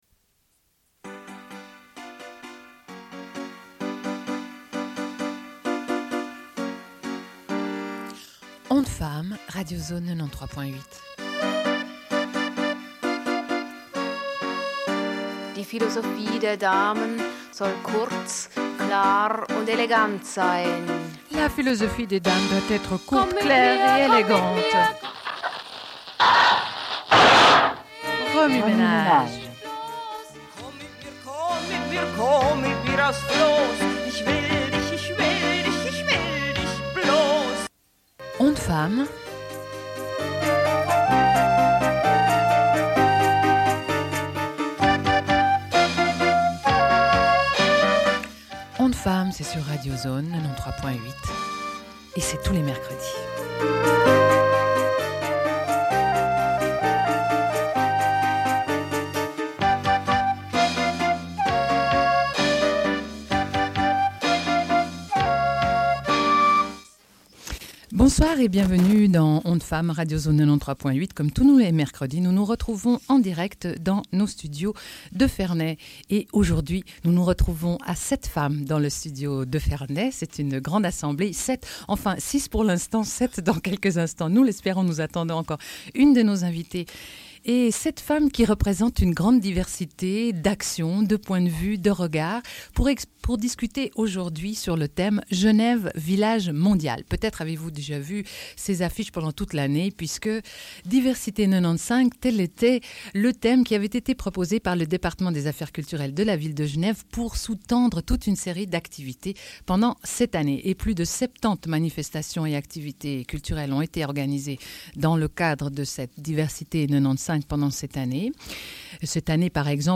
Radio Enregistrement sonore